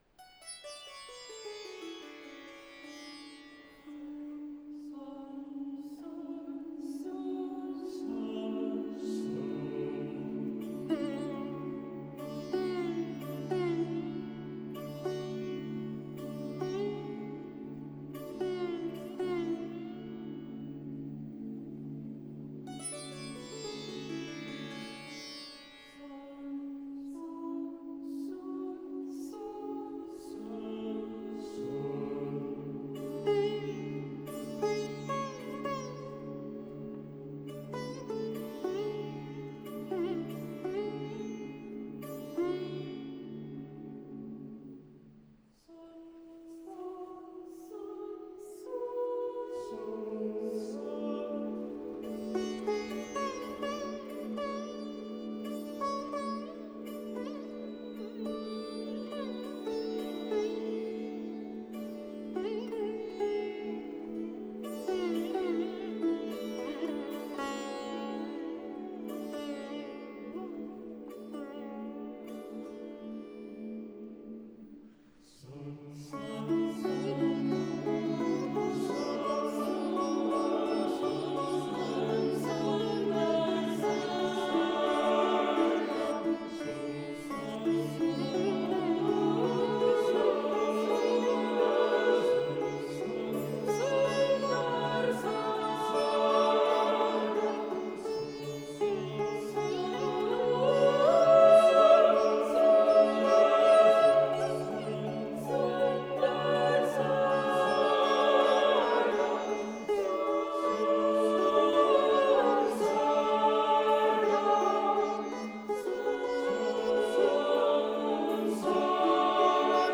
Genre: Indian Classical
is for voices and sitar
• The musical conversation between the sitar and the choir.